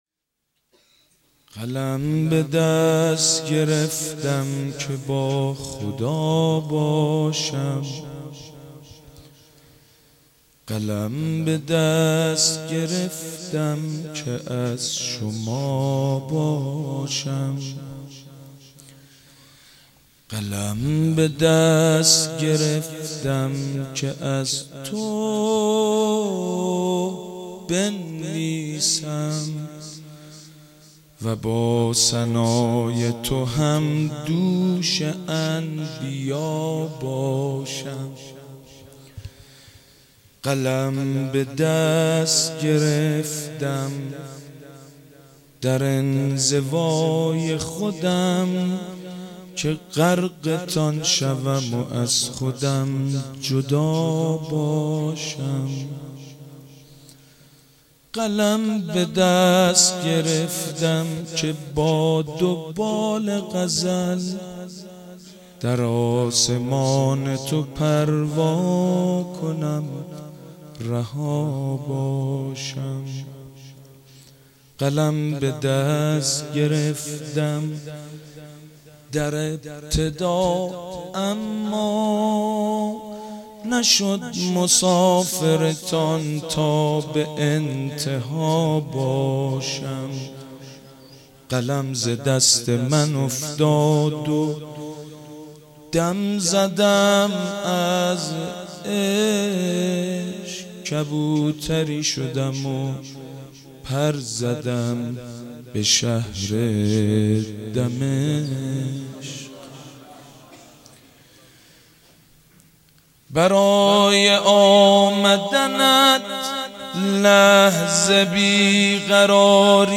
ولادت حضرت زینب سلام الله علیها 1392 | هیات ریحانه الحسین | حاج سید مجید بنی فاطمه